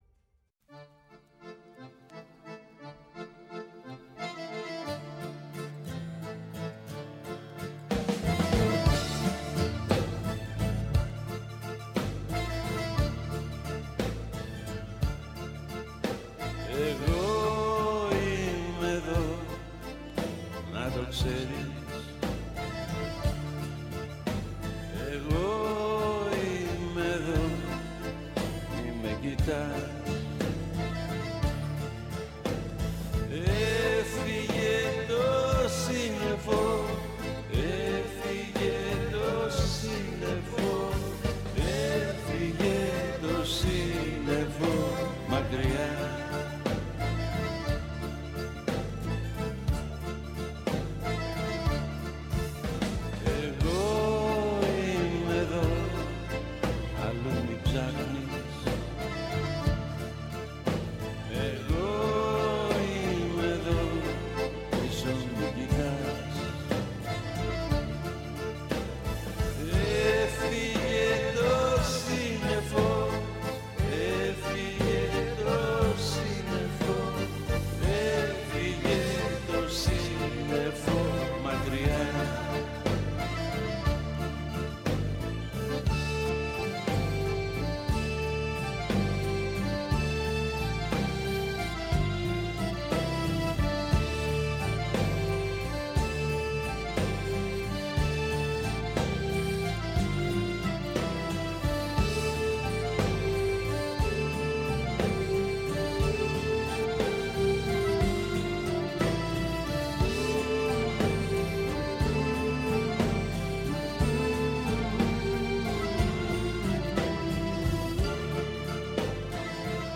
Με μουσικές από την Ελλάδα και τον κόσμο.